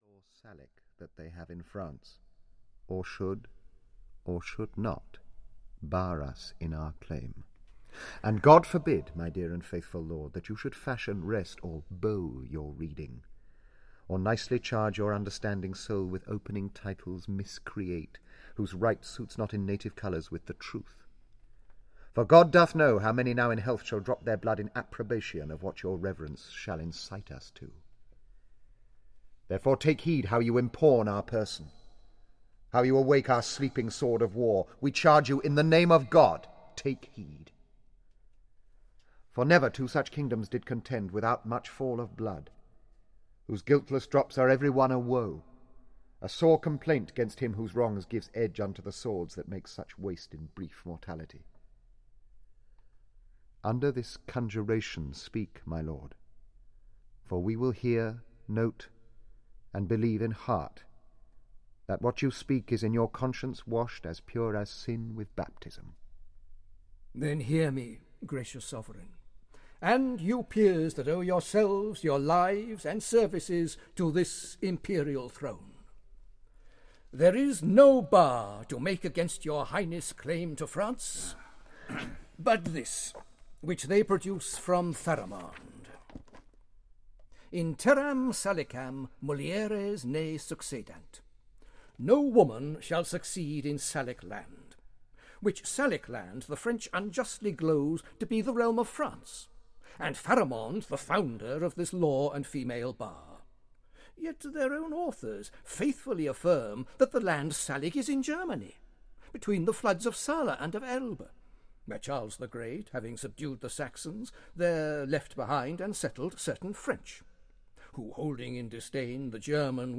Henry V (EN) audiokniha
Ukázka z knihy